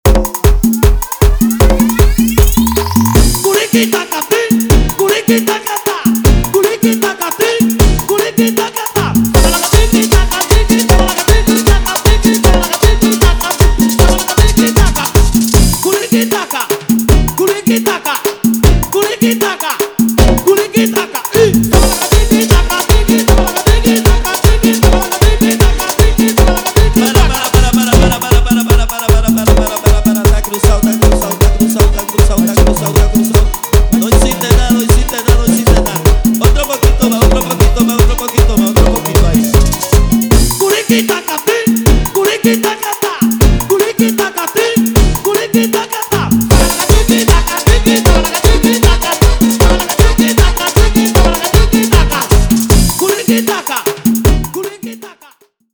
guaracha, salsa remix, cumbia remix, EDM latino